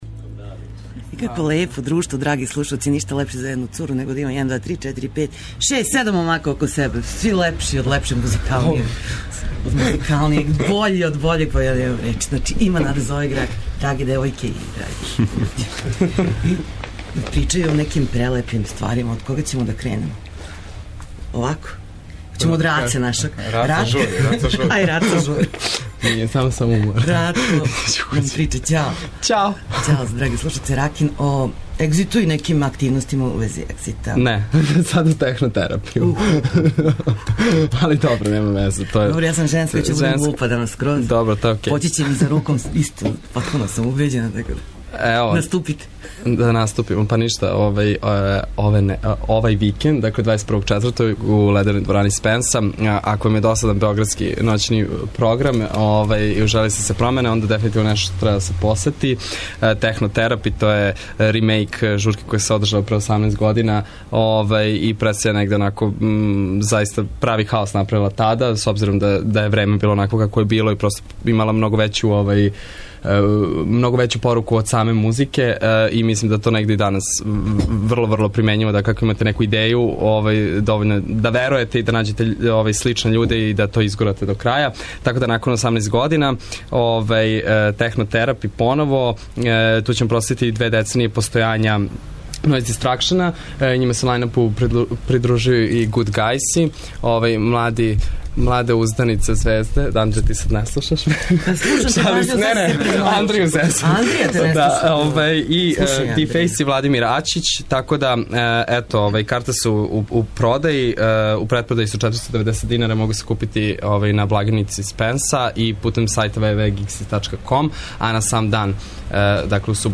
У госте нам долазе састави Жене Кесе, Синестезија, Болесна штенад и YU Grupa. Причамо о концертима који се приближавају, како ових састава, тако и о 'великим' концертима у нашем граду овог пролећа.